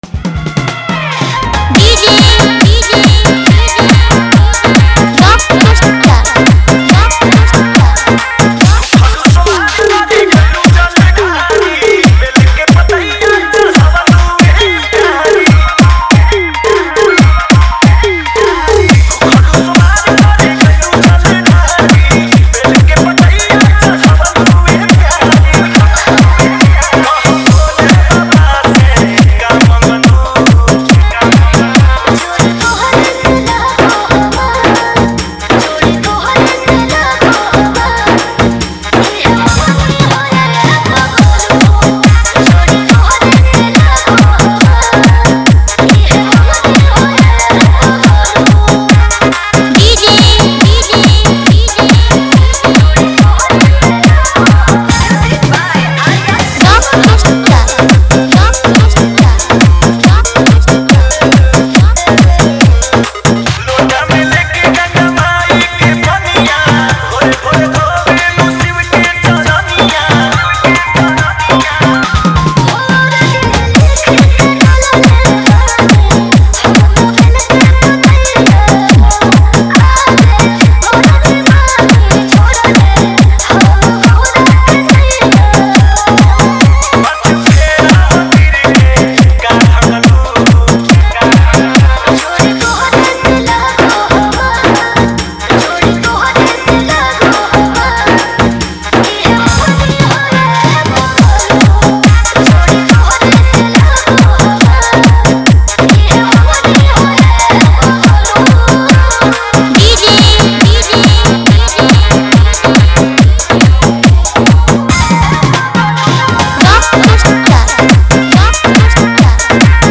Category:  Bol Bam 2023 Dj Remix Songs